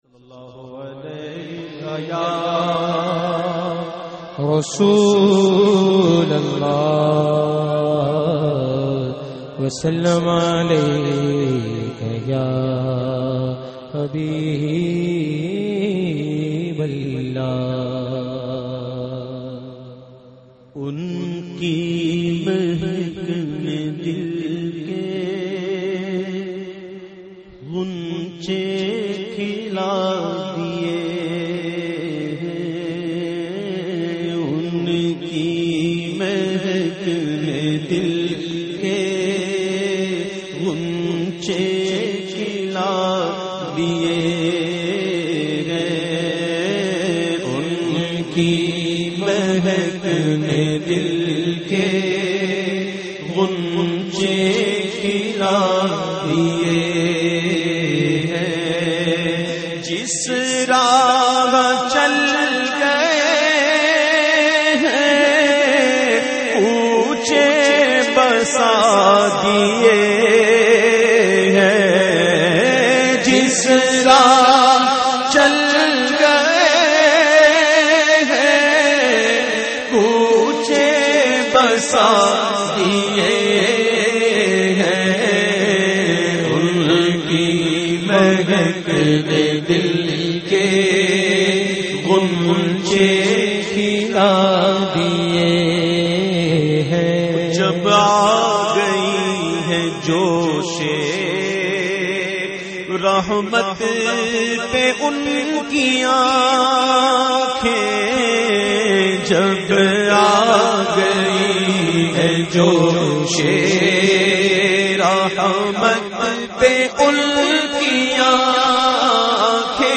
The Naat Sharif Unki Mehek Ne Dil Ke recited by famous Naat Khawan of Pakistan Owais Qadri.